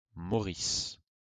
1. ^ /məˈrɪʃ(i)əs, mɔː-/ mər-ISH-(ee-)əs, mor-; French: Maurice [mɔʁis, moʁis]